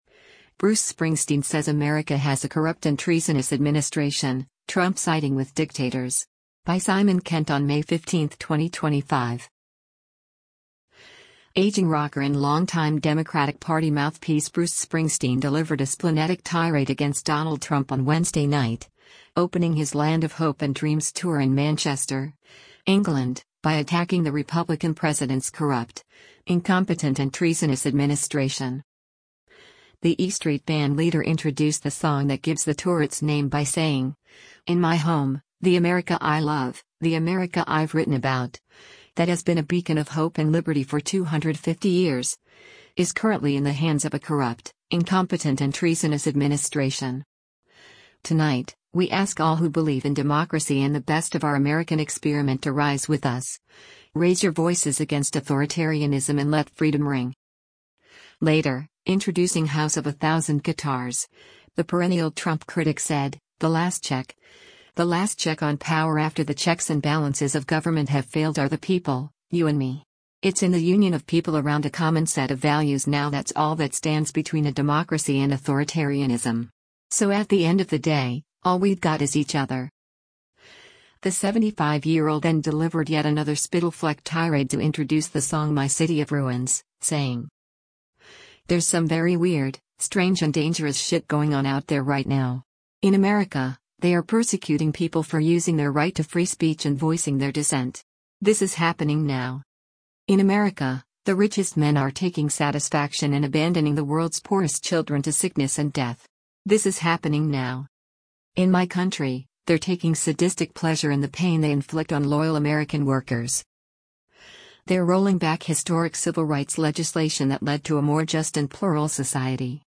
Aging rocker and longtime Democratic Party mouthpiece Bruce Springsteen delivered a splenetic tirade against Donald Trump on Wednesday night, opening his “Land of Hope and Dreams” tour in Manchester, England, by attacking the Republican president’s “corrupt, incompetent and treasonous administration.”
The 75-year-old then delivered yet another spittle-flecked tirade to introduce the song My City of Ruins, saying: